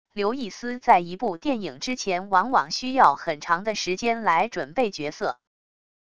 刘易斯在一部电影之前往往需要很长的时间来准备角色wav音频生成系统WAV Audio Player